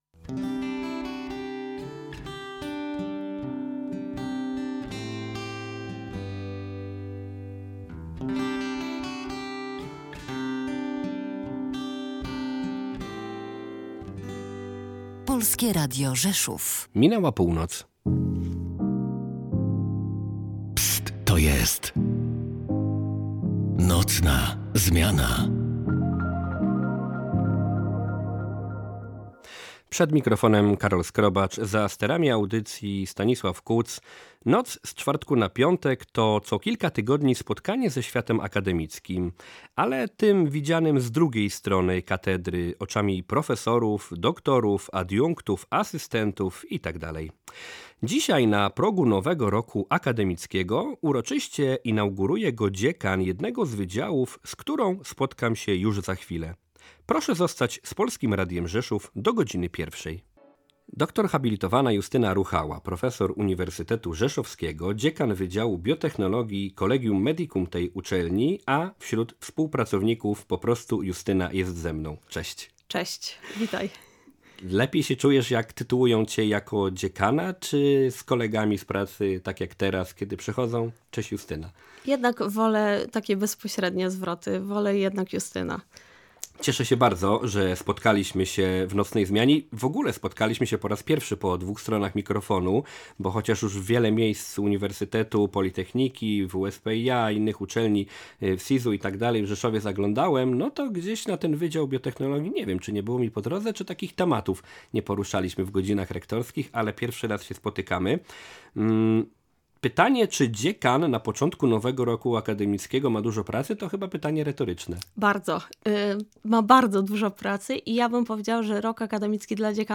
nocna-zmiana-3.10-na-strone-bez-muzyki.mp3